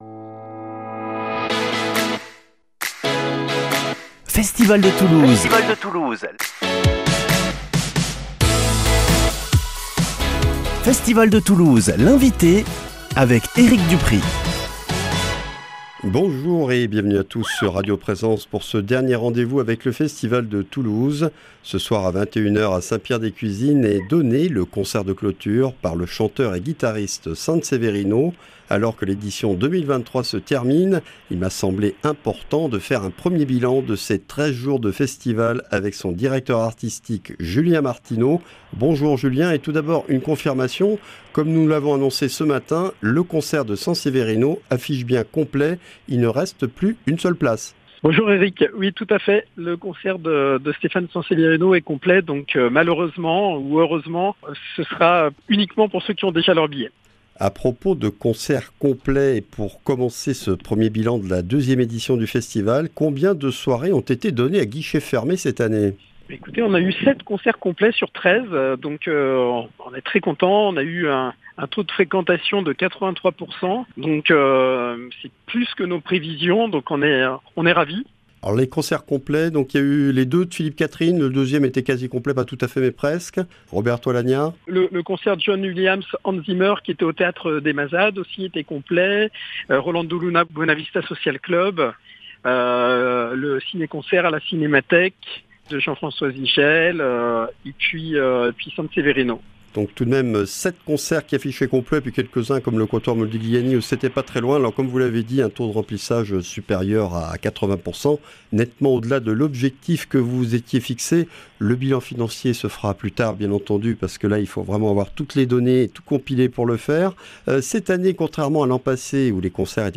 jeudi 13 juillet 2023 Festival de Toulouse - Interviews Durée 12 min